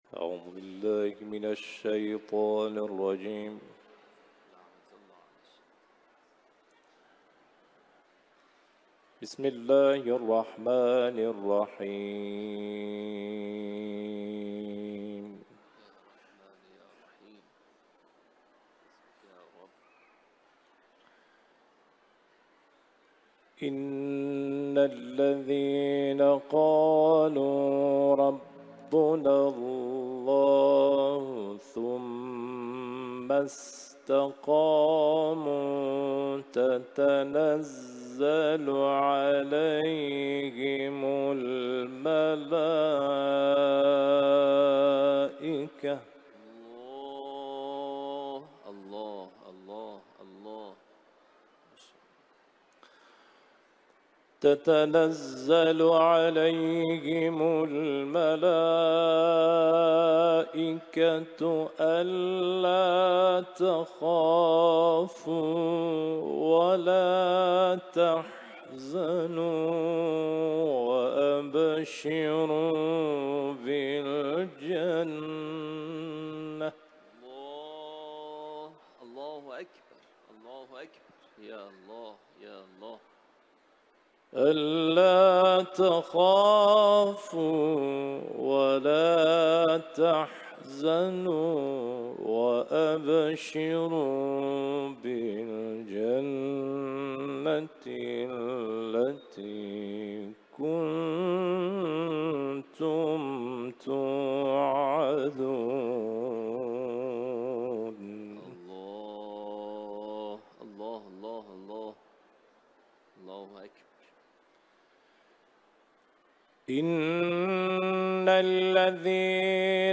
تلاوت قرآن ، سوره فصلت